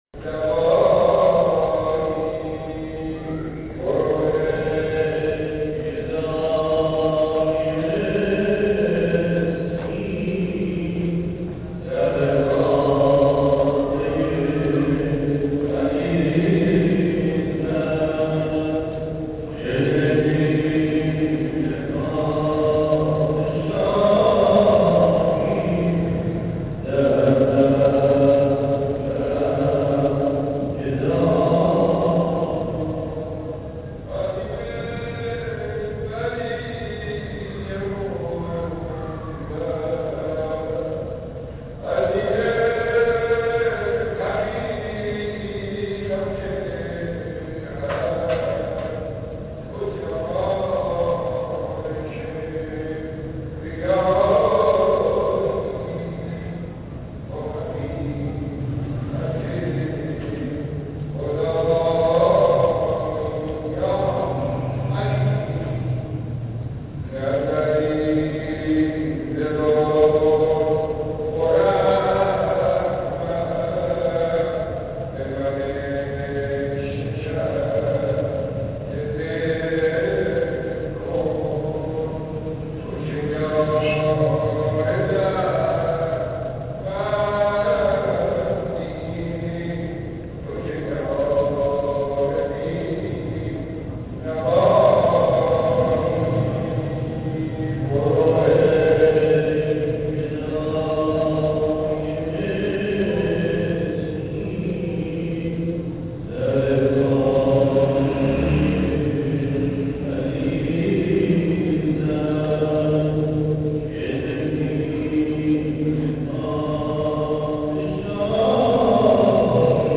Passant un matin devant la mosquee Hakim, la plus vieille d'Esfahan, nous avons voulu jeter un coup d'oeil dans la cour. Nous sommes restés là, assis au soleil, écoutant les mélopées d'un chant d'enterrement qui venaient resonner sous une voûte.
Enterrement.mp3